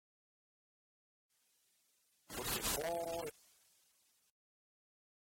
uitspraak Uitgesproken als 'des petits ronds'
potiron_mot.mp3